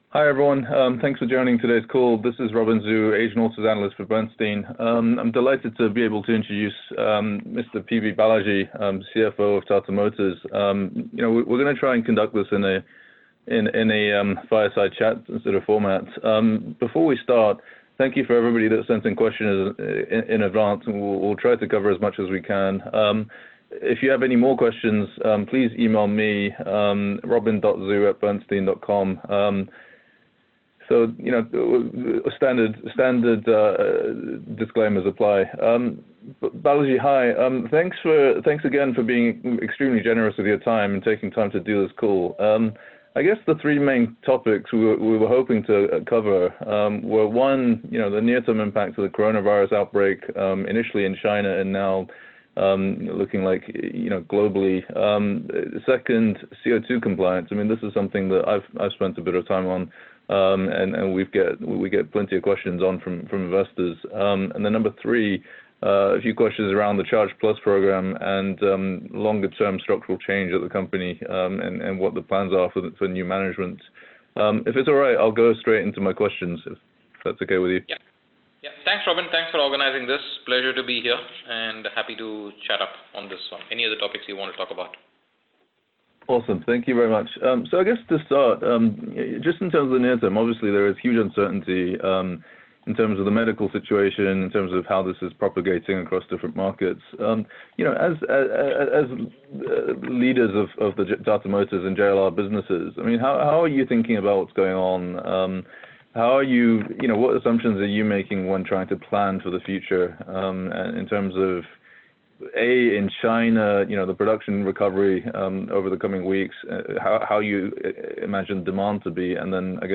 Tata Motors Investor Conference call – organised by Bernstein
tata-motors-investor-conference-call.mp3